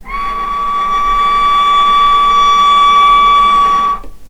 vc-C#6-mf.AIF